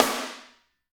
SNARE 053.wav